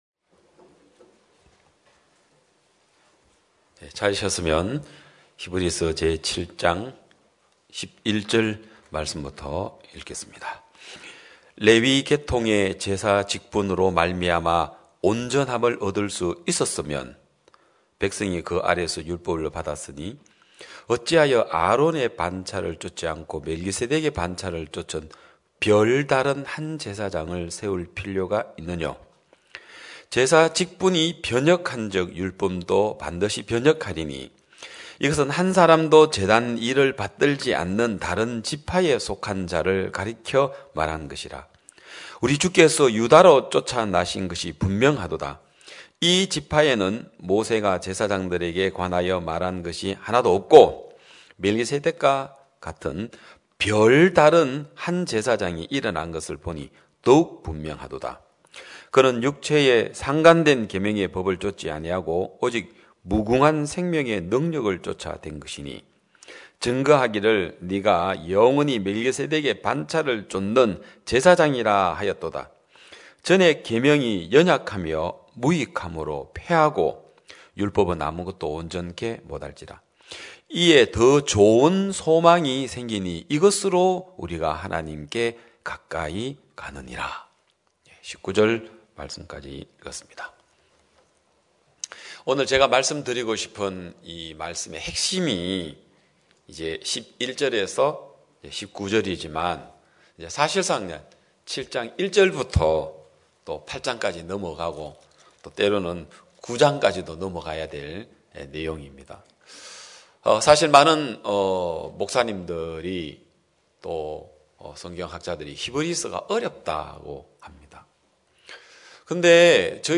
2021년 11월 28일 기쁜소식양천교회 주일오전예배
성도들이 모두 교회에 모여 말씀을 듣는 주일 예배의 설교는, 한 주간 우리 마음을 채웠던 생각을 내려두고 하나님의 말씀으로 가득 채우는 시간입니다.